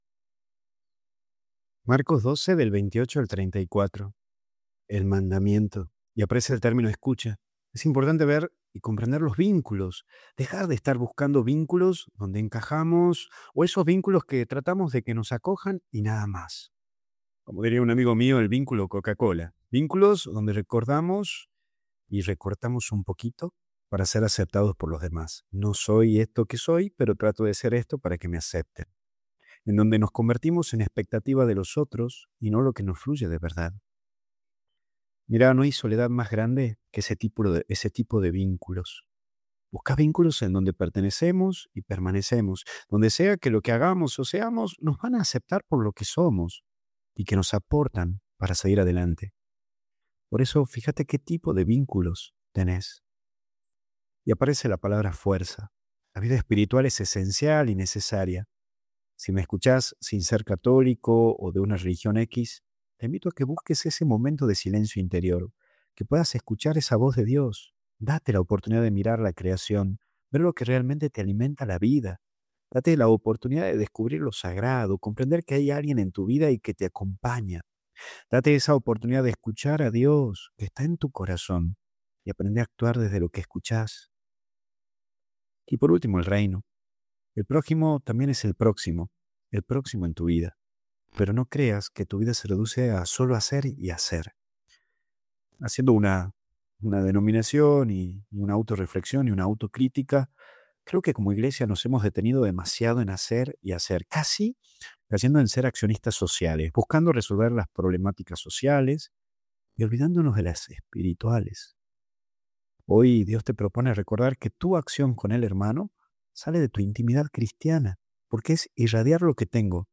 Audios meditacion del Evangelio